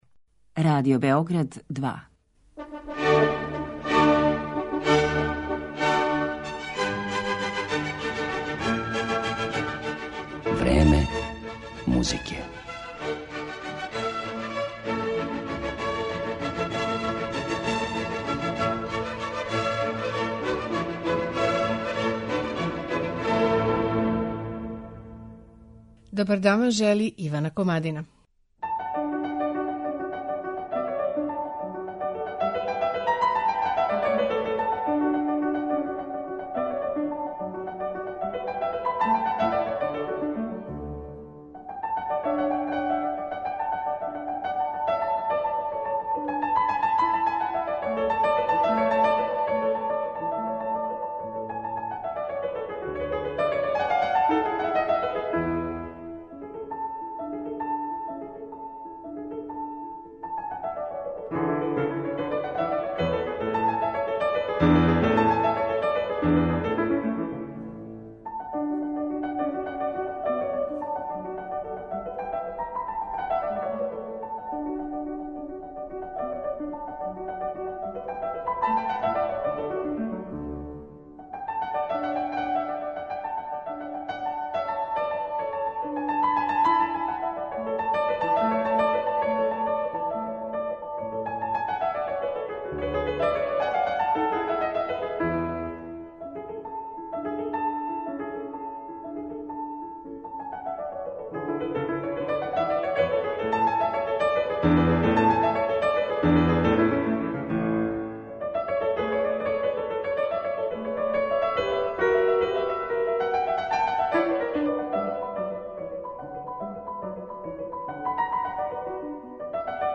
Хавијер Перијанес, клавир